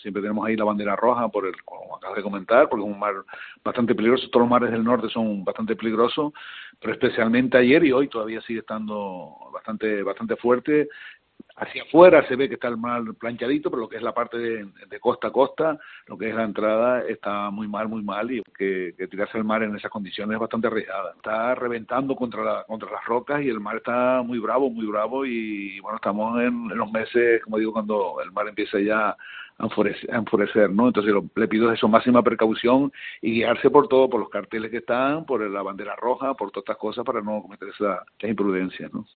Mariano Pérez, alcalde de El Sauzal